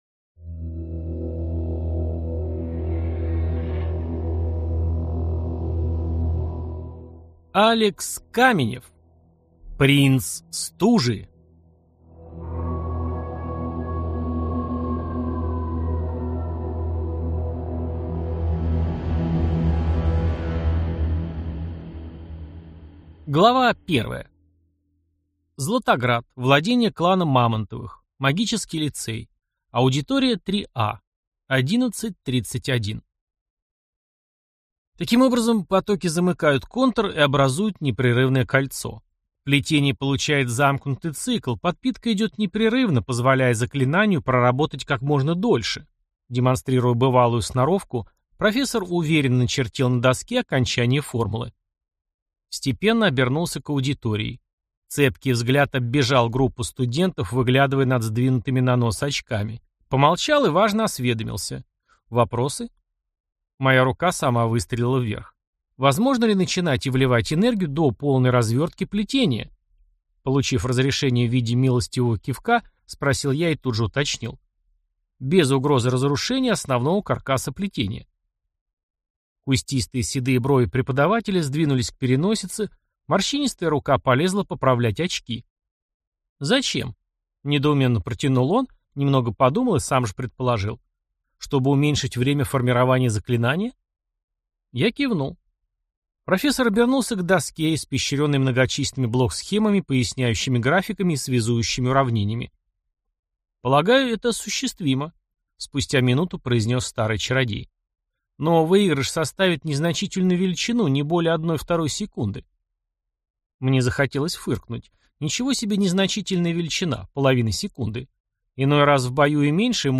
Аудиокнига Цитадели гордыни. Принц стужи | Библиотека аудиокниг